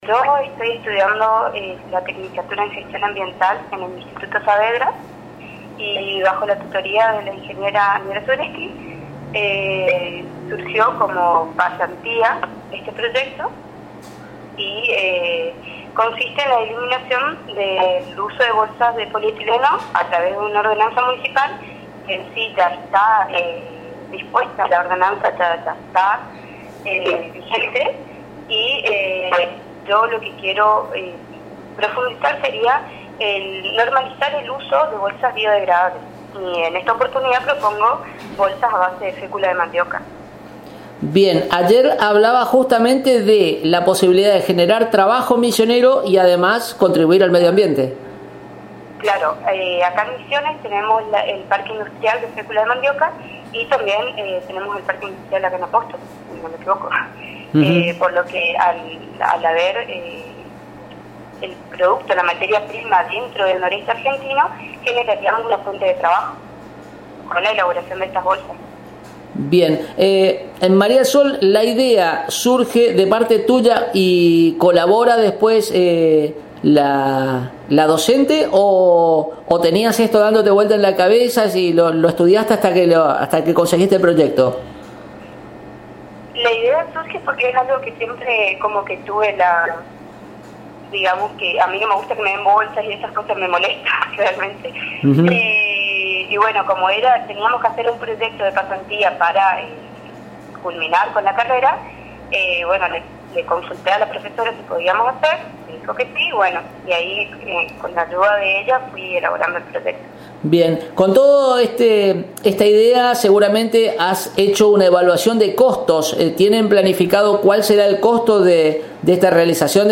En charla telefónica